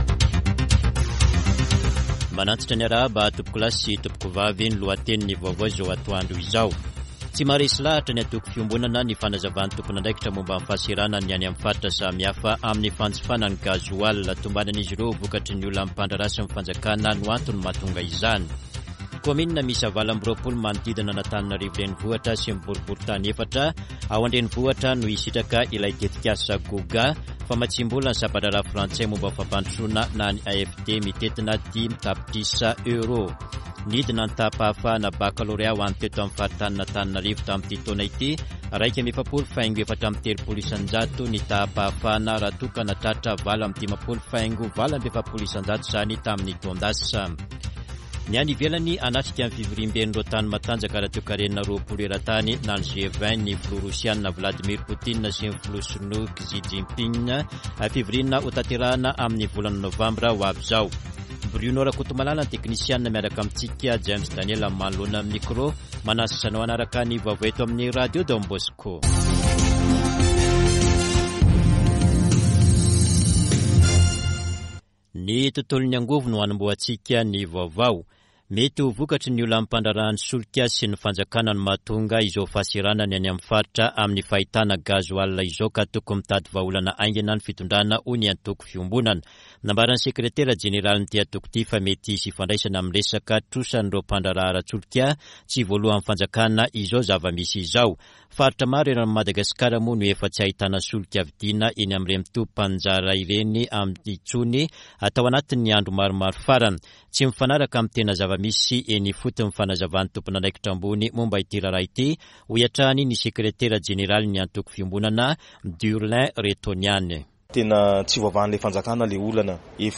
[Vaovao antoandro] Sabotsy 20 Aogositra 2022